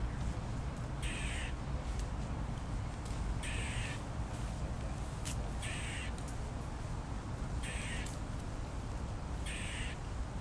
Squalking squirrel
76935-squalking-squirrel.mp3